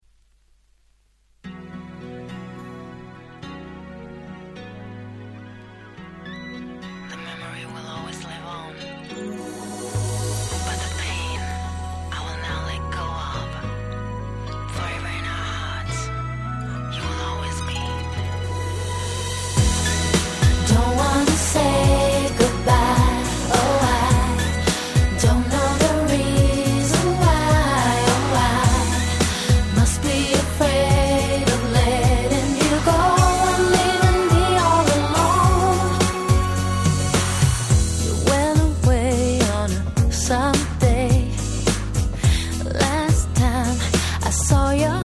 EUのR&Bがお好きな方はマストな1枚！！